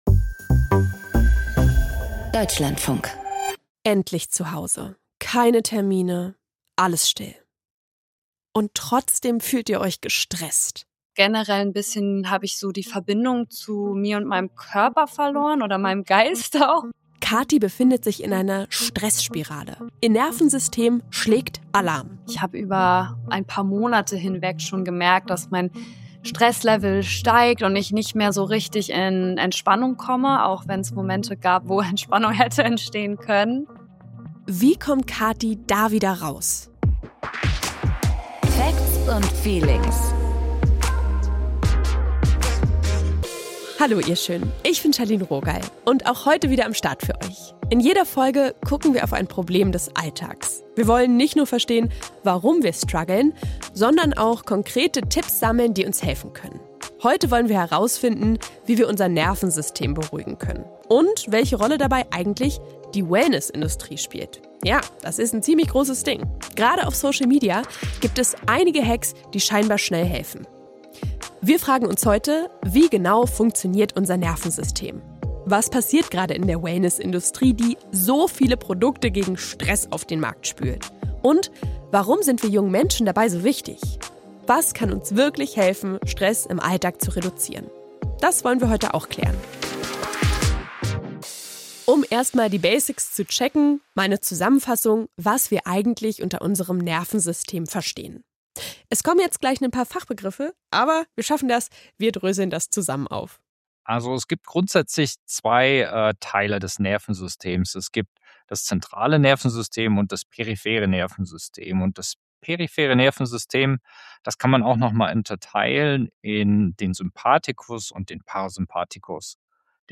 Gesprächspartnerin